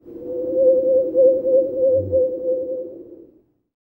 TUV NOISE 05.wav